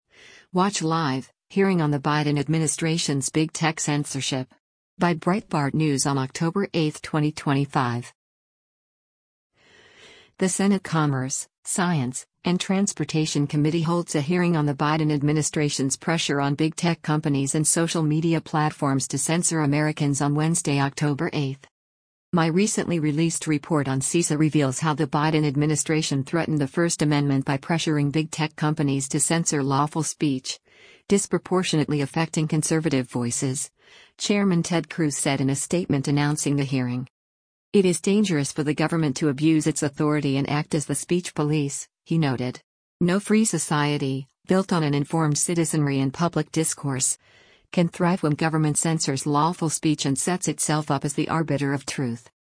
The Senate Commerce, Science, and Transportation Committee holds a hearing on the Biden administration’s pressure on big tech companies and social media platforms to censor Americans on Wednesday, October 8.